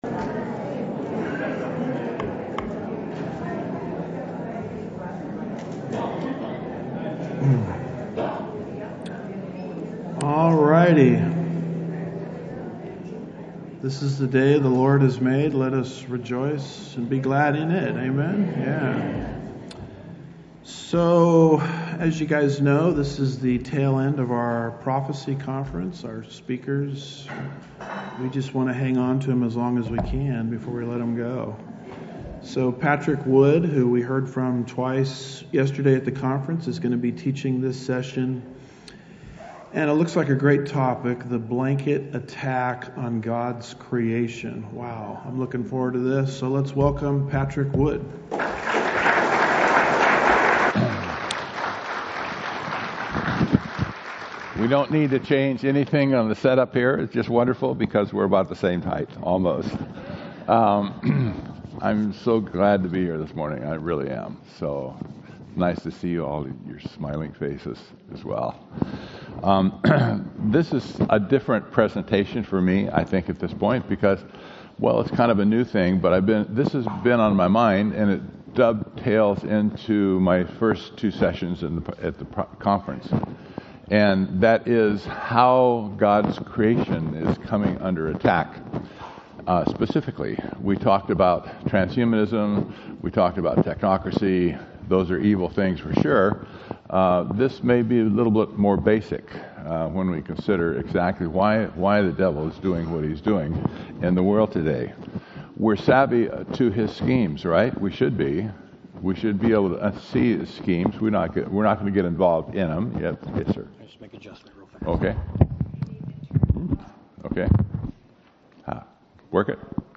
2026 Prophecy Conference